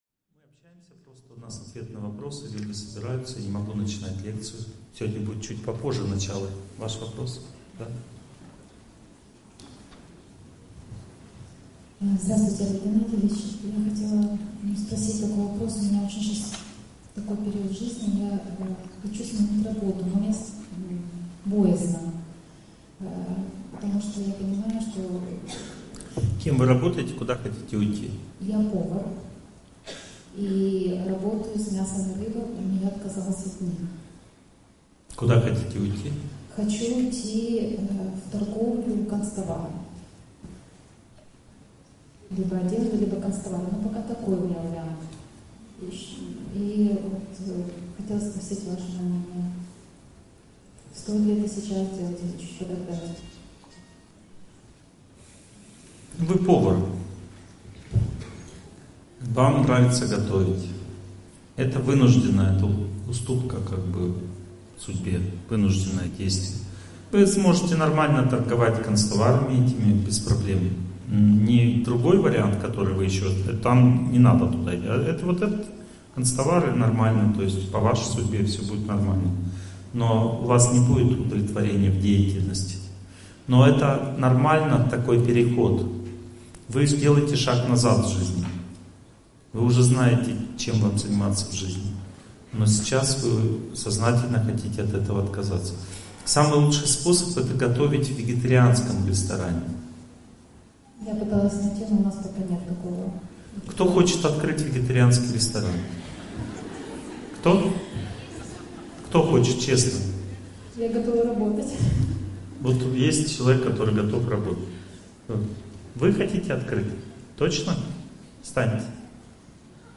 Sekreti-uspeha-zhizni-v-megapolise-Lekciya-1.mp3